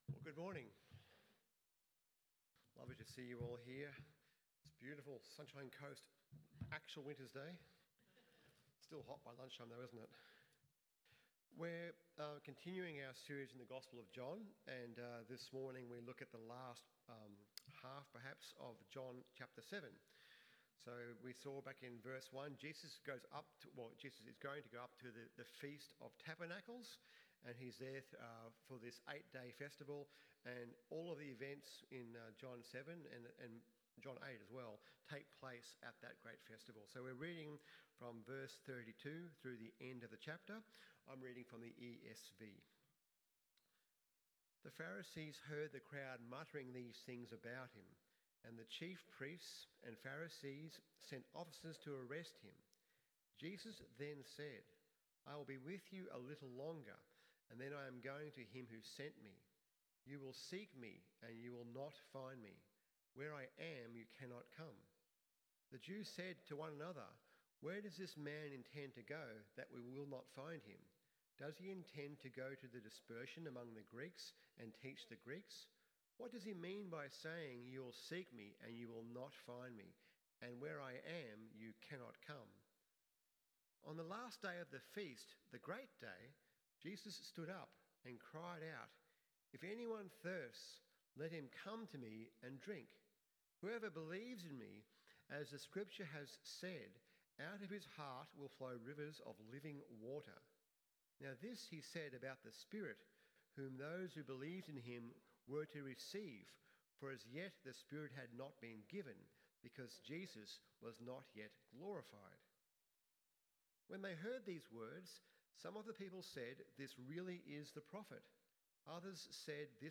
Who Is This Man? AM Service
sermon podcasts